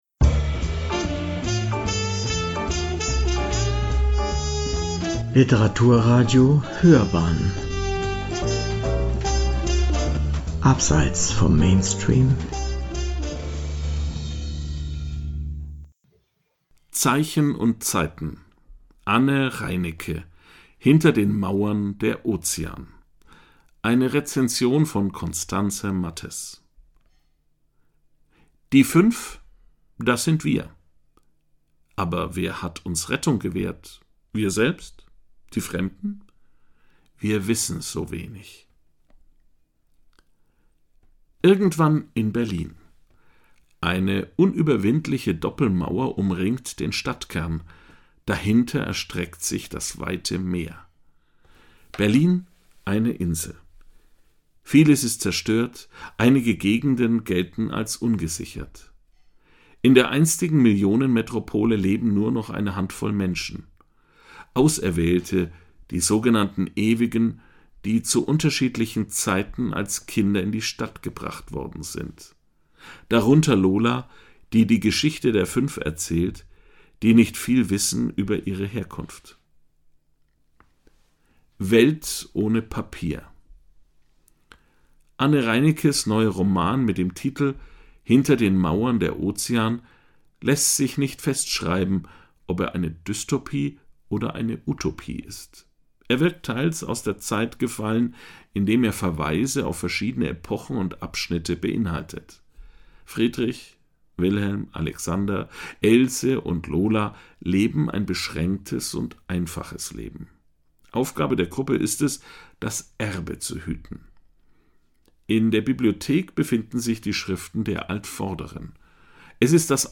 “Hinter den Mauern der Ozean” von Anne Reinecke – eine Rezension